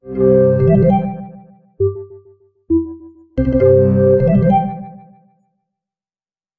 incoming-call.mp3